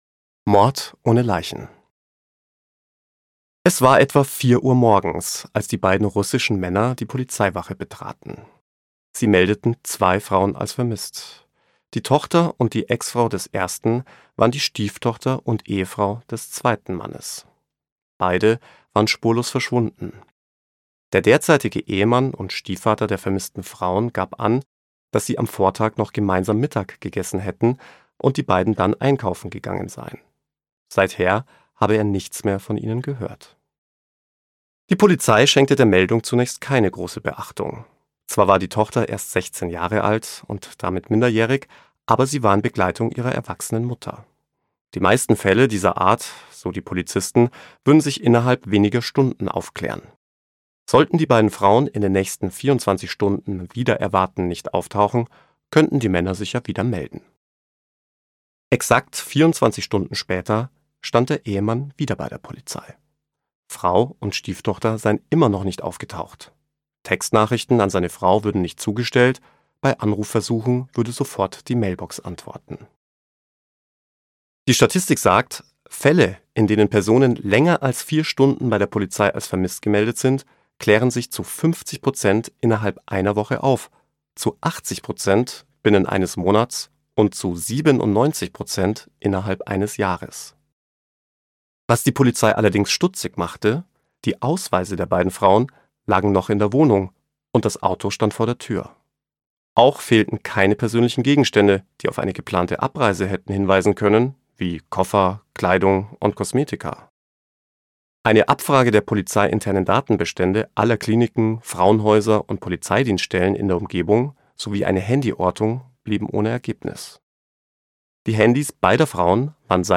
Der perfekte Mord? (DE) audiokniha
Ukázka z knihy